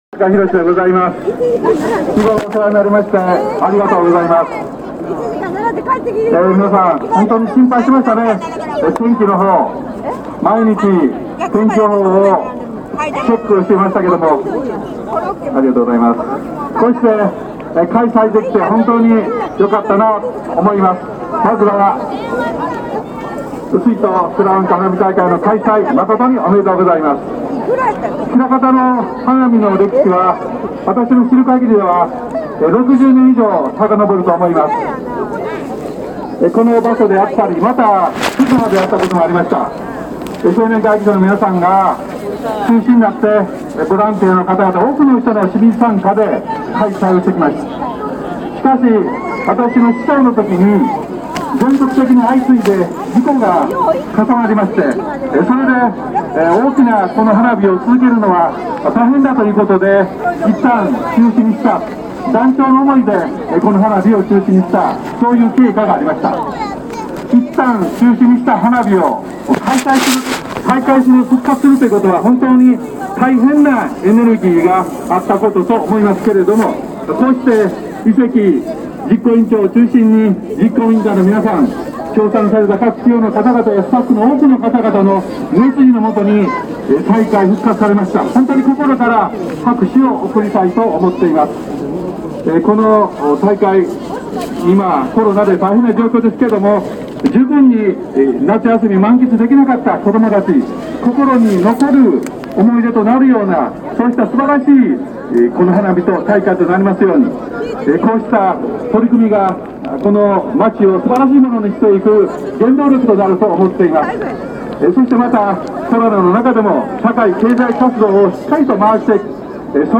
中司衆議院議員のあいさつ（第一回 水都くらわんか花火大会）